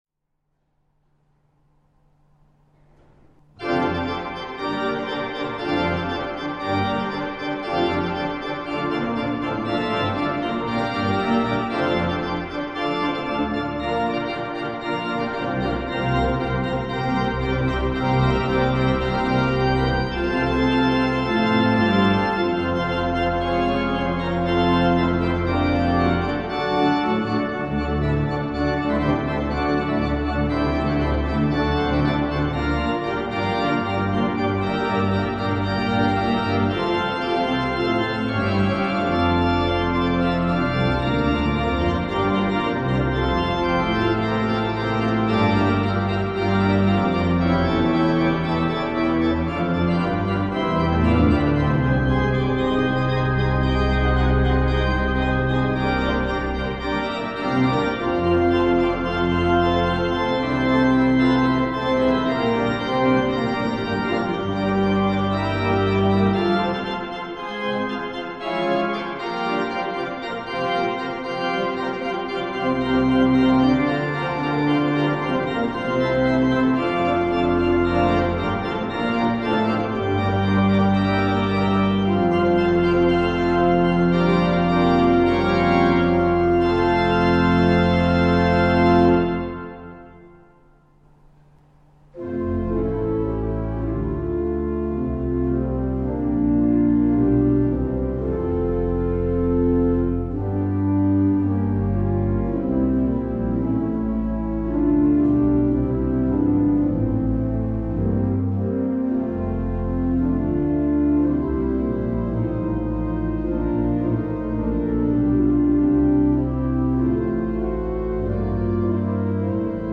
An der wunderbar weich und etwas mystisch klingenden Rieger-Orgel von 1933, die in unserer Kirche Christus-König in Adlershof steht, sind gestern improvisierte Variationen über dieses Lied entstanden.